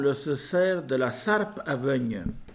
Localisation Sainte-Christine
Catégorie Locution